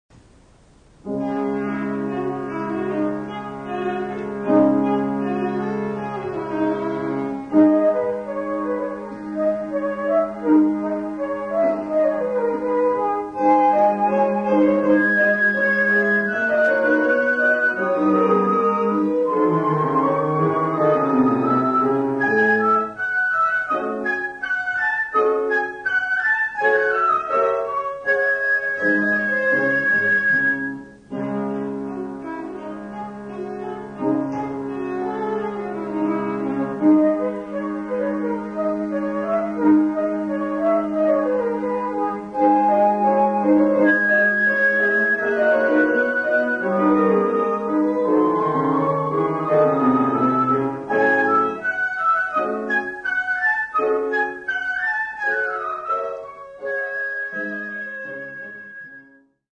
Muchas grabaciones que aquí se ofrecen se registraron en presentaciones en vivo durante las décadas de 1950, 1960 y 1970.
Divertimento para flauta, violín, violoncello y piano (1959).